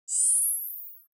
دانلود آهنگ ربات 57 از افکت صوتی اشیاء
دانلود صدای ربات 57 از ساعد نیوز با لینک مستقیم و کیفیت بالا
جلوه های صوتی